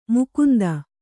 ♪ mukunda